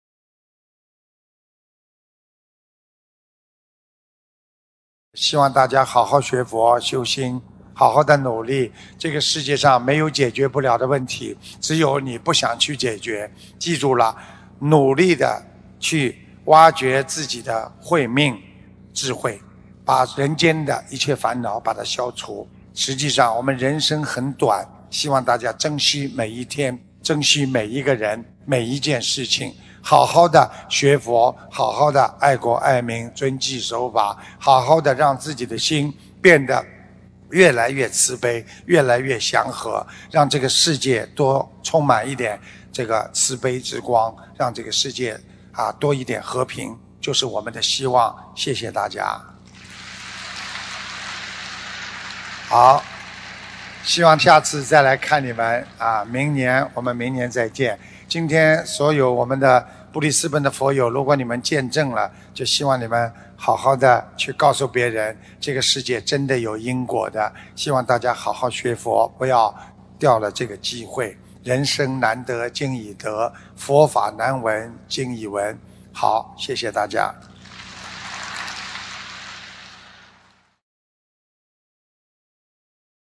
2018年6月17日布里斯本法会结束语-经典开示节选